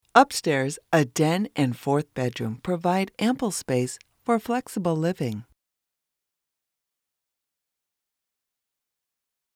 Sprechprobe: Industrie (Muttersprache):
My native tongue is North American English and my style is warm, trustworthy, professional, and authoritative.